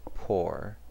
Ääntäminen
Ääntäminen US : IPA : [pɔːɹ]